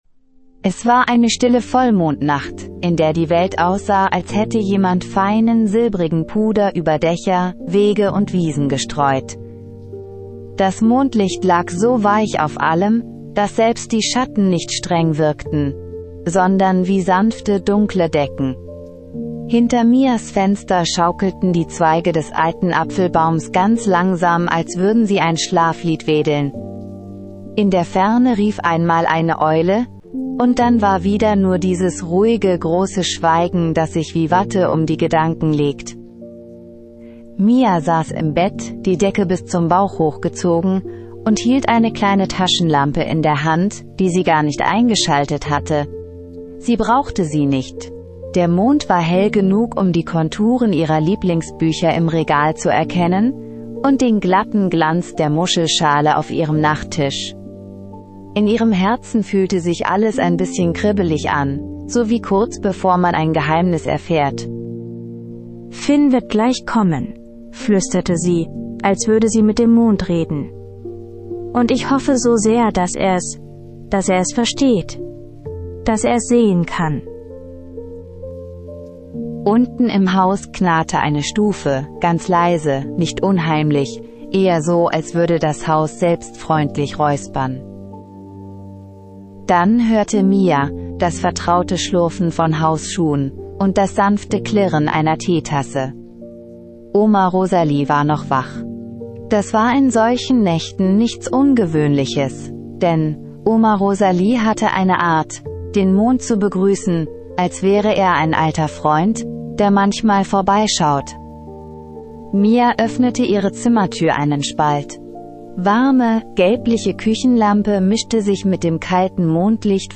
Mia bringt ihren Freund Finn mit in den Traumwald. Gemeinsam folgen sie einem Pfad aus leuchtenden Blumen zur weisen Eule Ophelia. Eine magische Einschlafgeschichte (ca. 55 Min).